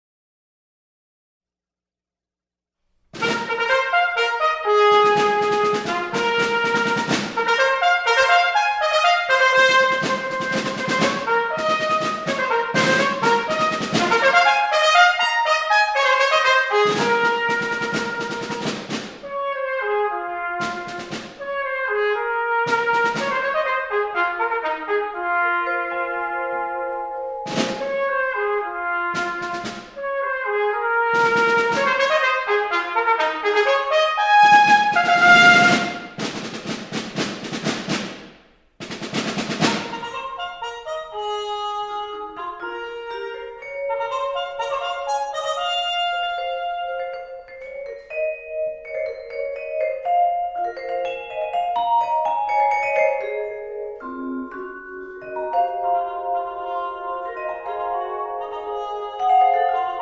trumpets